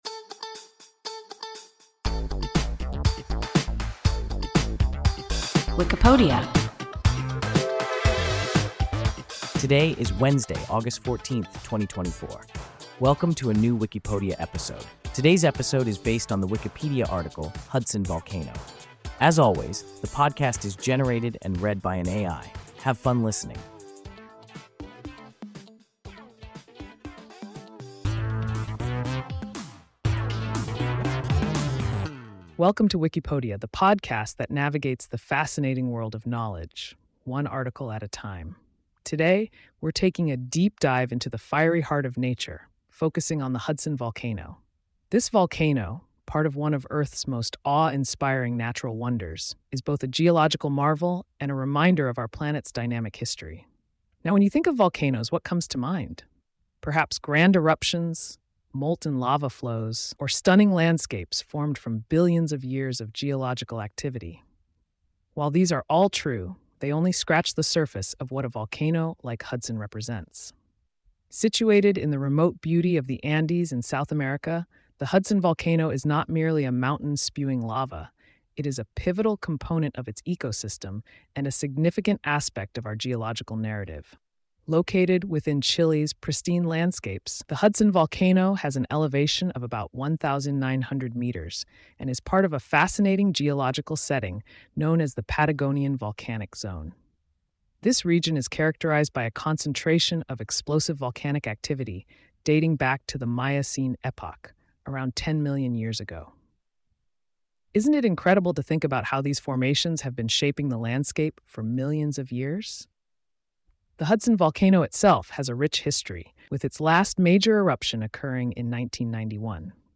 Hudson Volcano – WIKIPODIA – ein KI Podcast